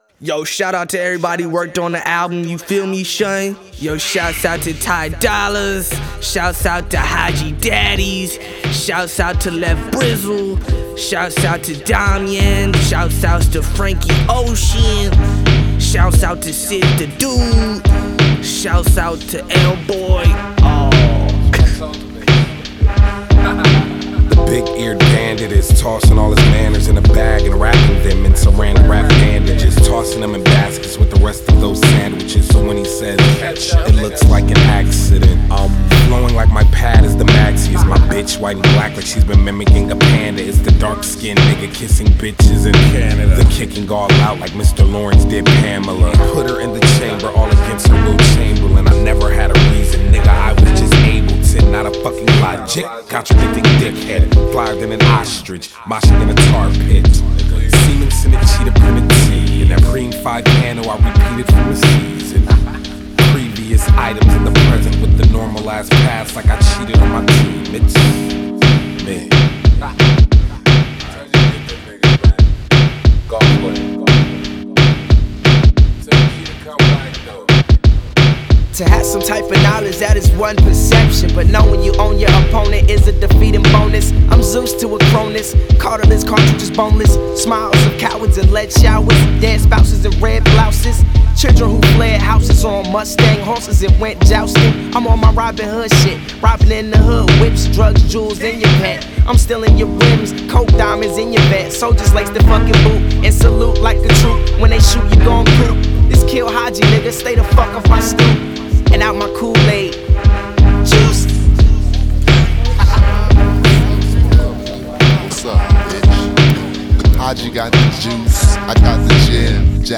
While its all hook, they are their own chorus.